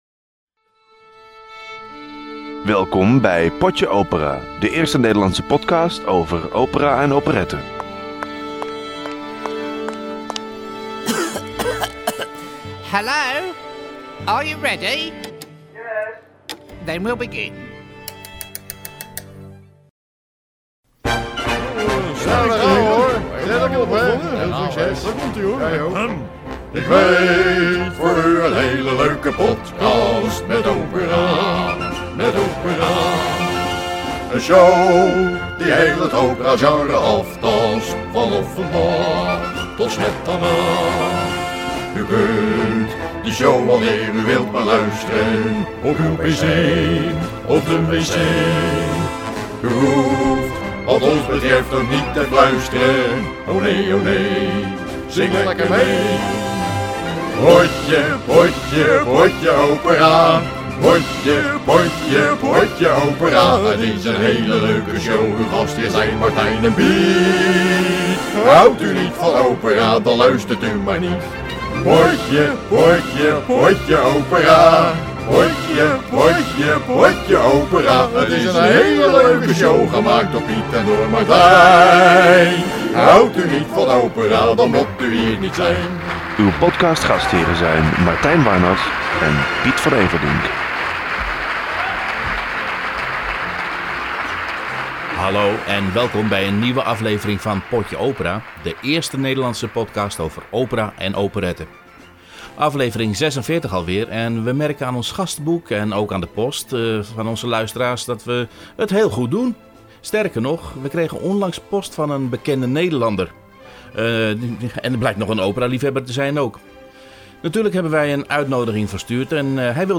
Gastpresentator: Peter R. de Vries